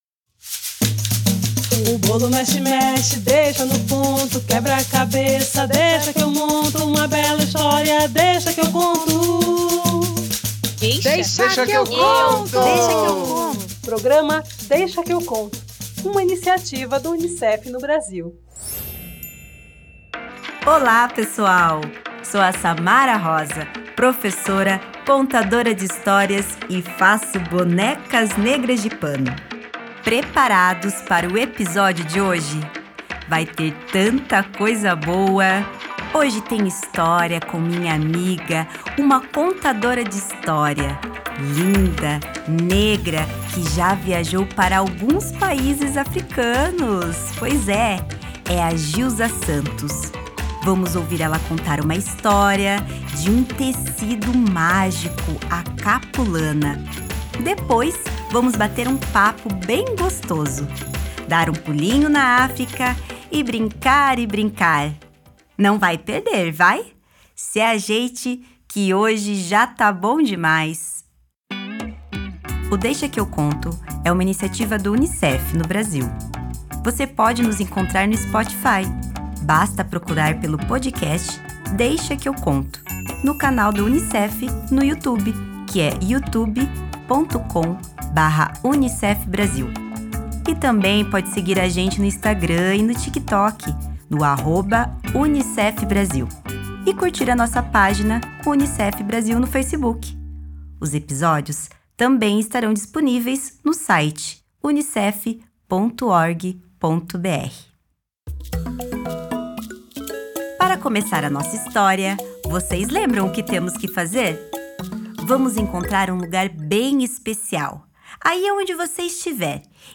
História Entrevista